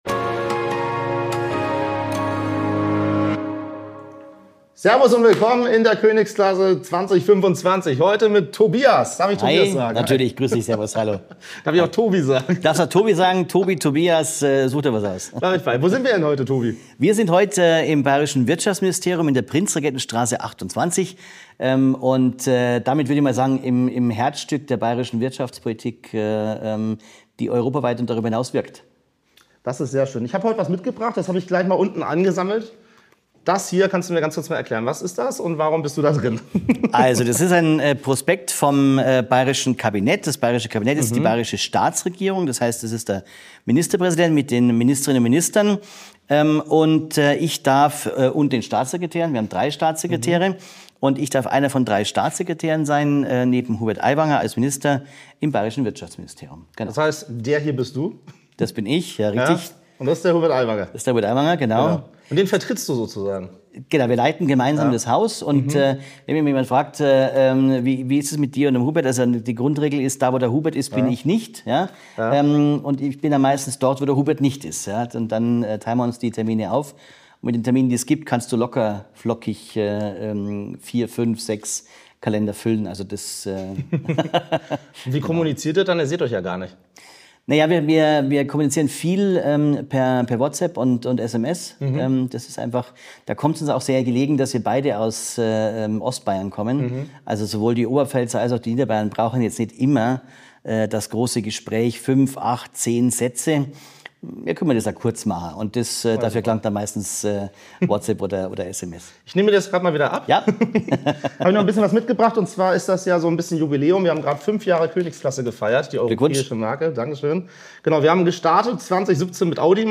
Staatssekretär im Wirtschaftsministerium Bayern Tobias im Gespräch